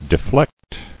[de·flect]